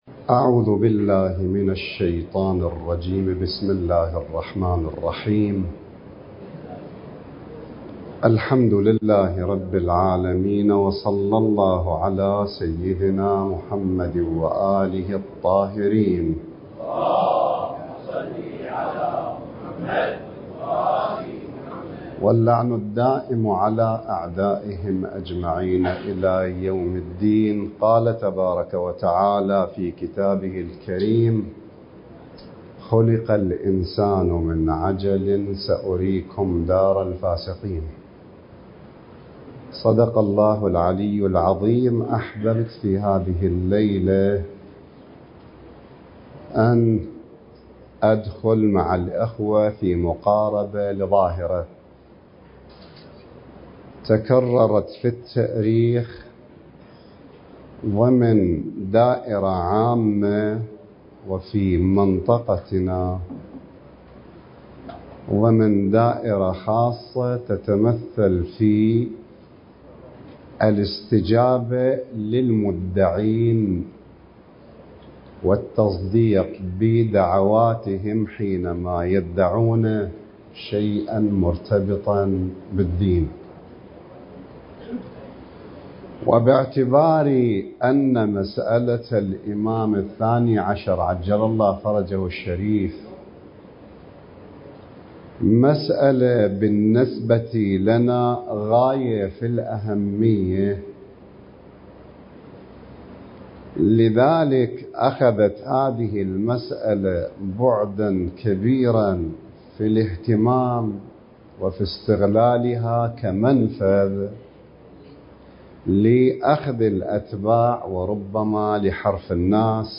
المكان: مركز خاتم الأوصياء (عجّل الله فرجه)/ جامع الرسول (صلّى الله عليه وآله) / بغداد